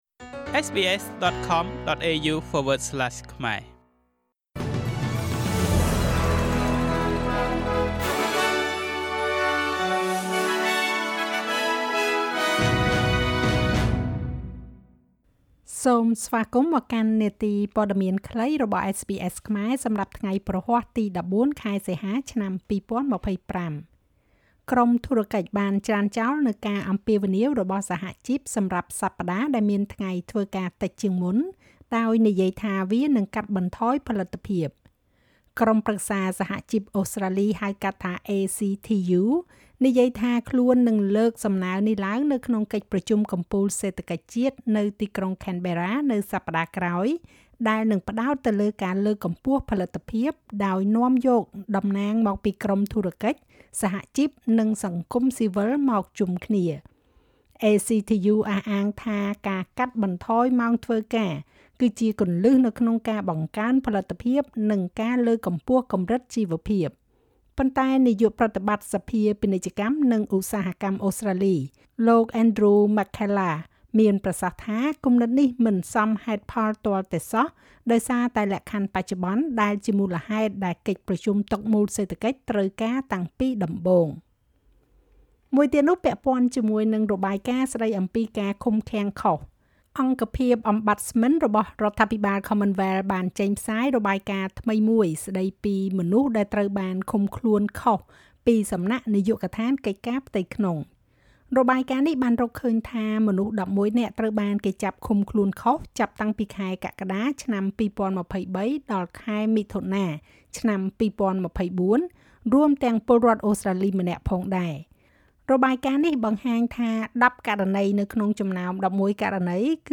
នាទីព័ត៌មានខ្លីរបស់SBSខ្មែរ សម្រាប់ថ្ងៃព្រហស្បតិ៍ ទី១៤ ខែសីហា ឆ្នាំ២០២៥